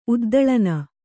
♪ uddaḷana